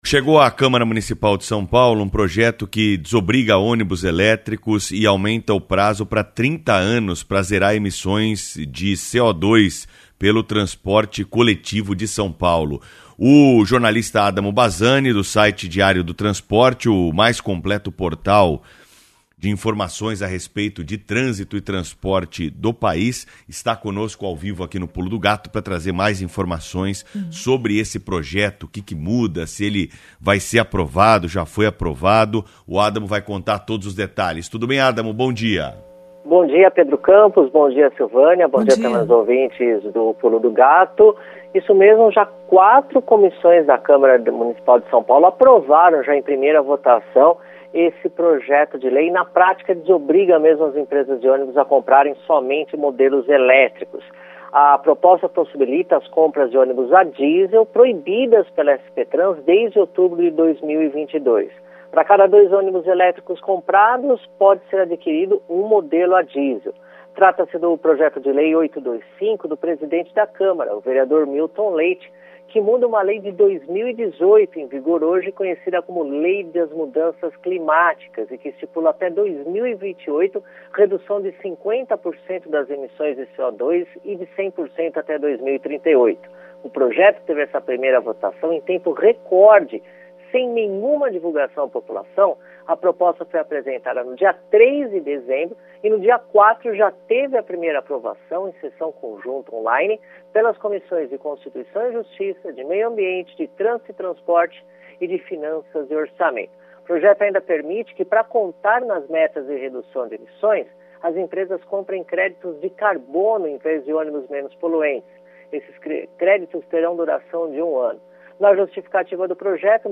jornalista especializado em transportes